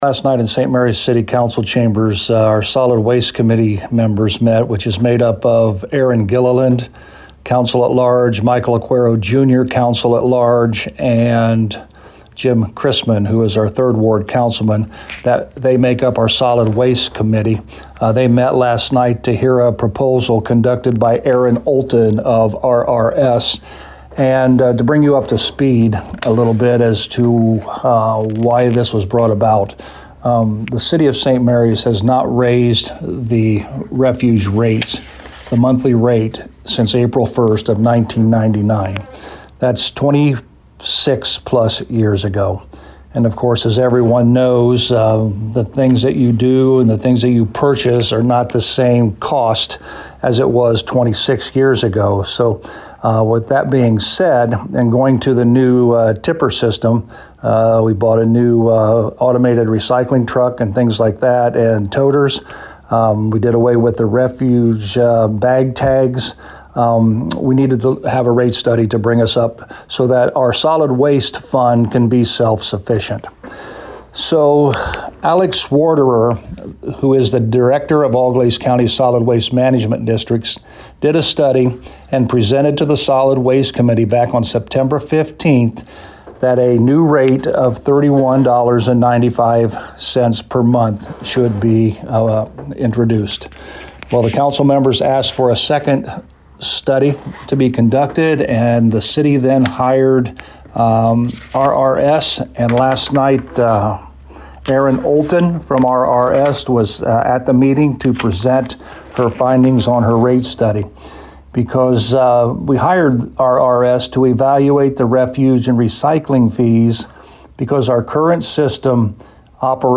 To hear the report with Mayor Hurlburt: